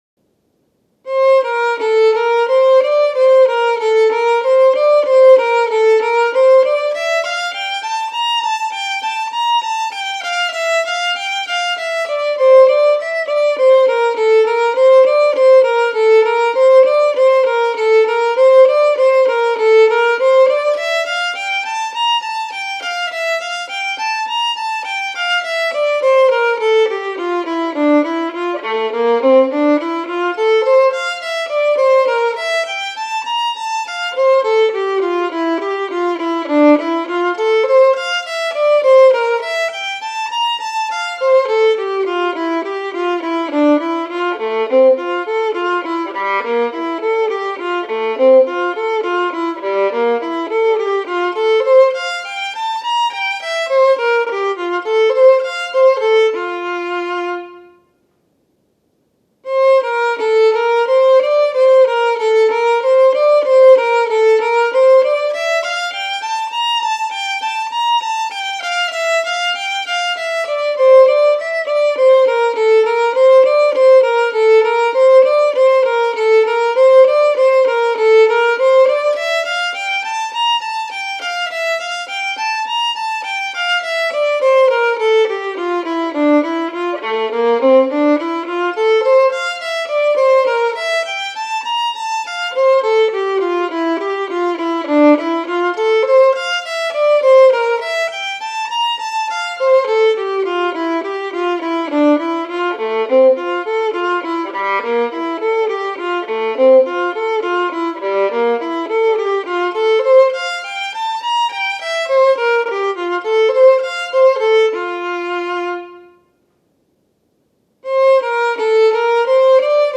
Una selección de estudios del libro 60 estudios para violín op. 45 del violinista y pedagogo alemán F. Wolfhart, en la edición de Frigyes Sándor.